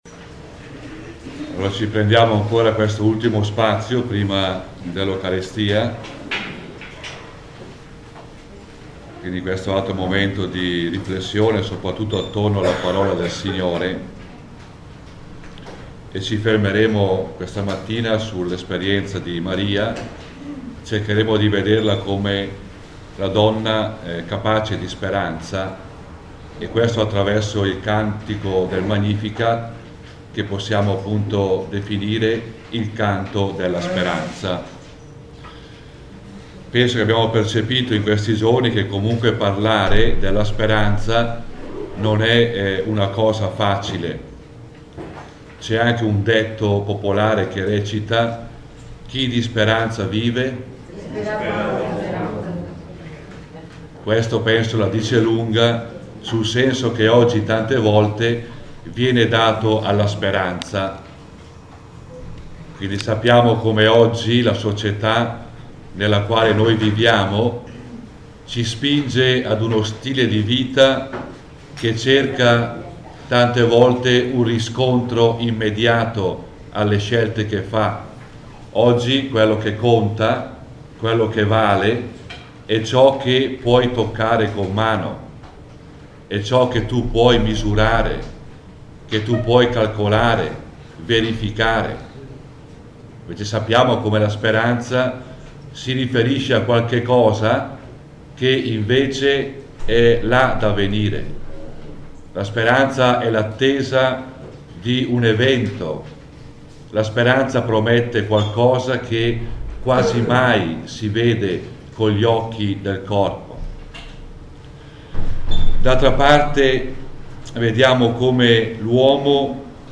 Nei giorni dal 6 al 8 Ottobre 2006 si è tenuto a Loreto il 3° Raduno nazionale dei gruppi Monfortani, seguendo nelle giornate di spiritualità il tema proposto :